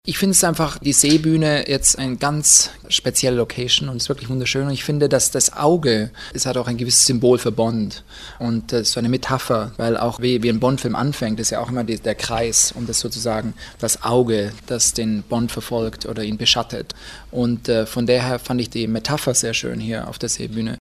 O-Ton Marc Forster über Bondfilm
oton_marc_forster_waehrend_bregenzdreharbeiten.mp3